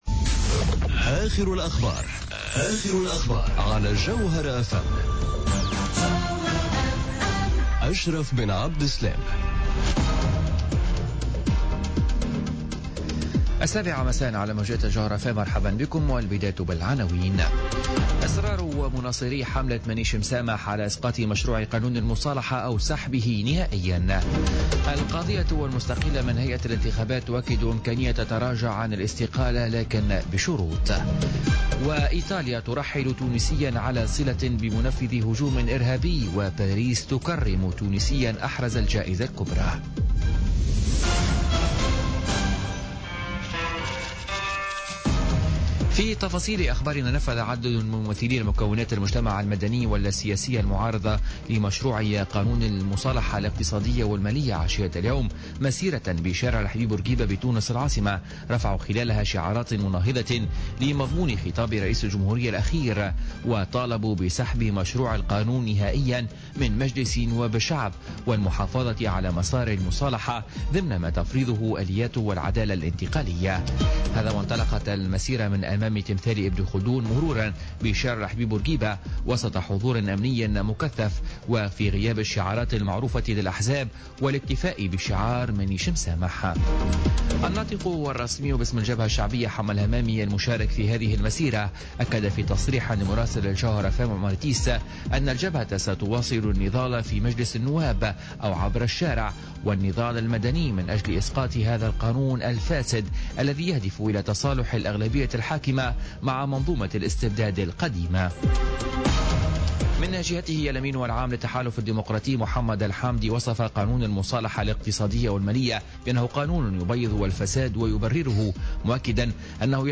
نشرة أخبار السابعة مساء ليوم السبت 13 ماي 2017